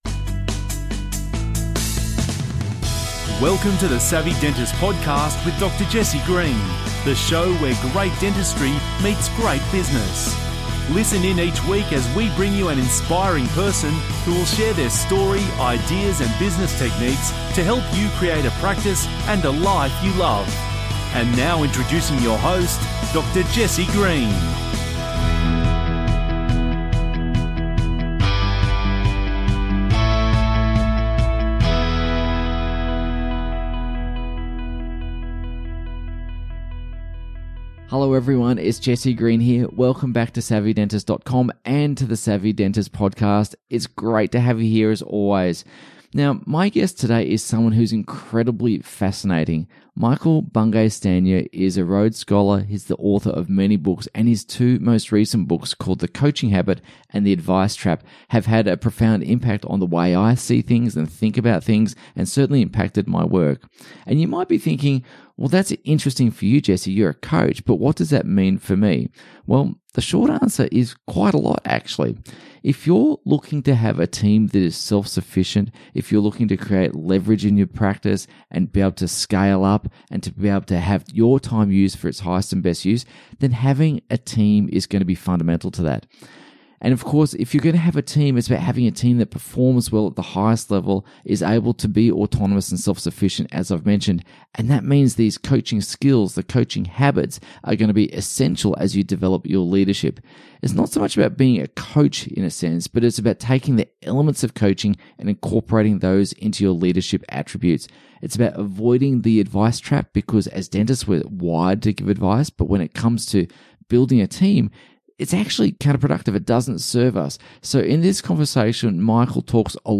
There are a lot of pearls of wisdom i n our conversation as Michael talks about the elements of leadership and making yourself a more effective team leader.